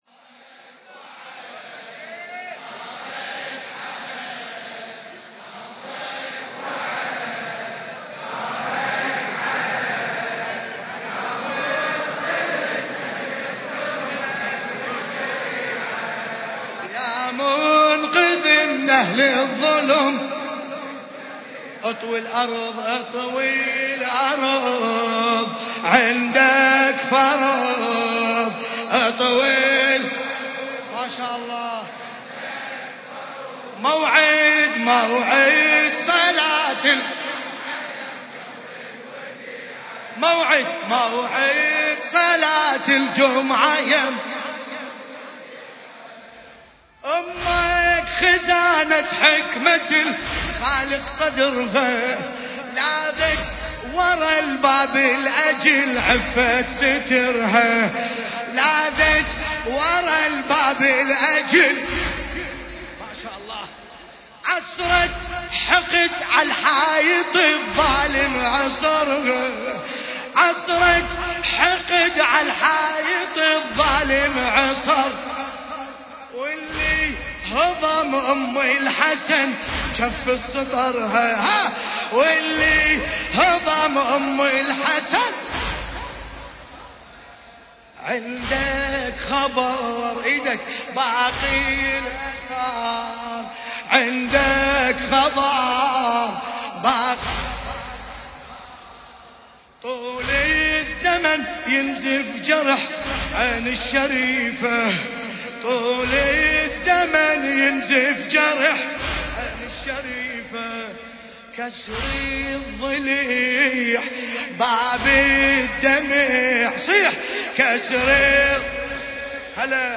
المكان: العتبة العلوية المقدسة/ موكب النجف الاشرف
القارئ : الحاج باسم الكربلائي